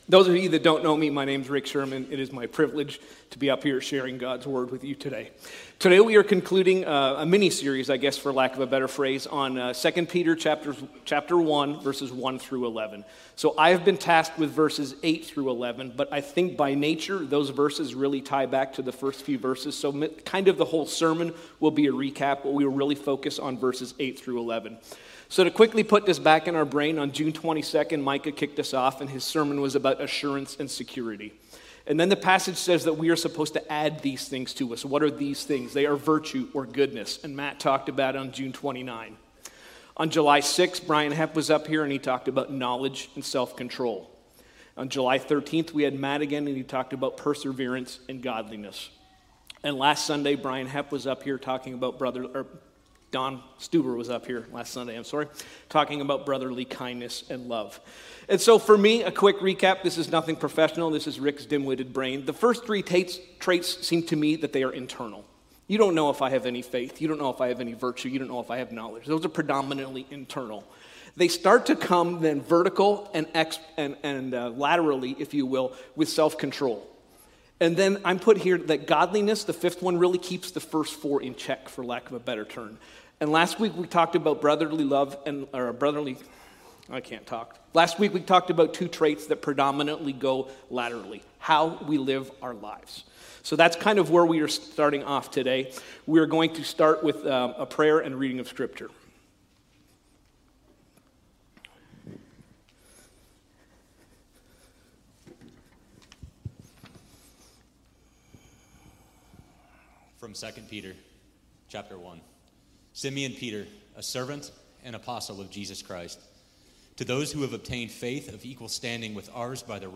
Northfield Christian Fellowship - Sermons Podcast - Make Every Effort | 2 Peter 1 Series | Free Listening on Podbean App